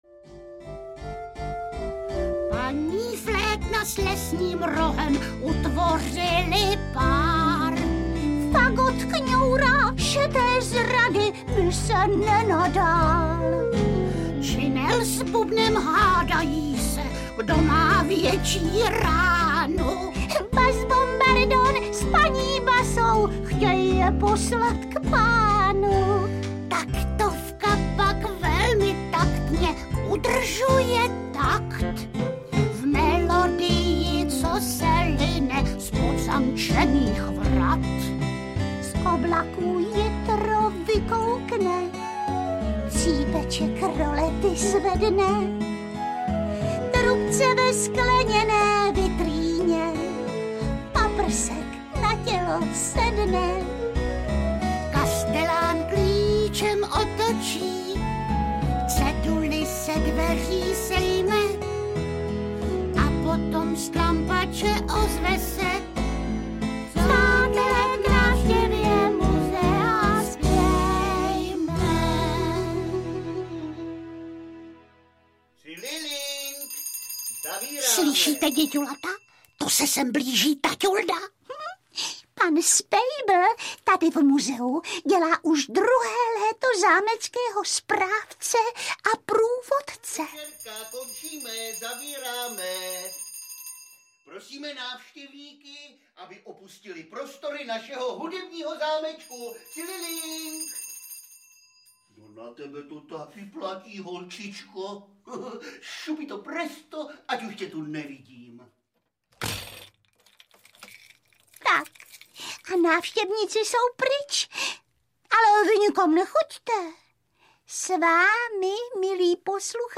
Hurvínkovy hudební pohádky 2 audiokniha
Ukázka z knihy